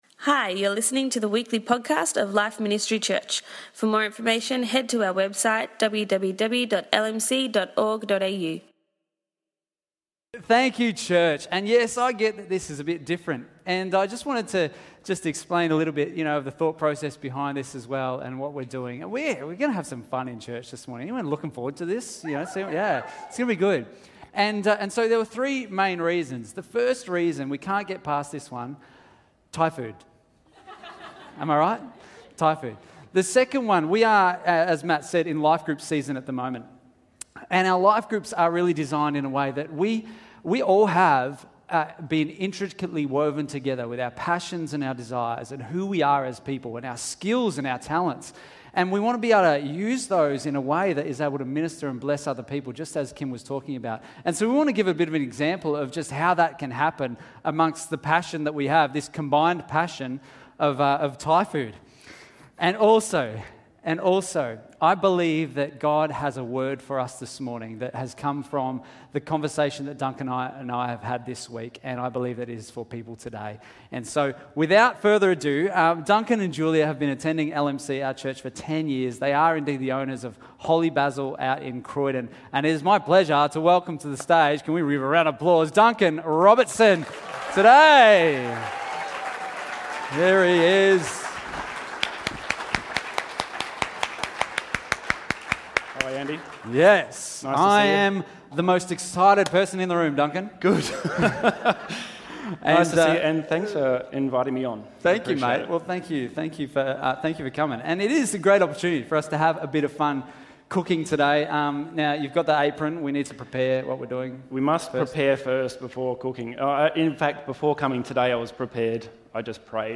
Due to the interactive nature of this message, we recommend viewing via YouTube.